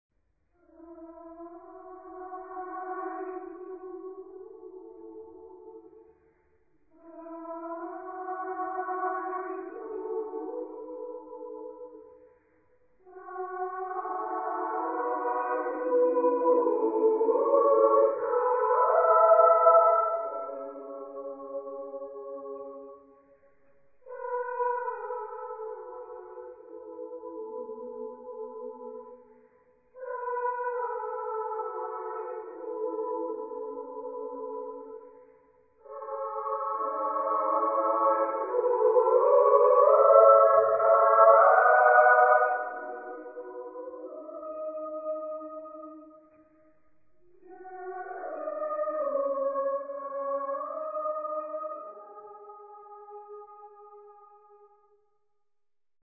Genre-Style-Form: Mass ; Sacred ; Romantic
Mood of the piece: moderate
Type of Choir: TTB  (3 men voices )
Tonality: E major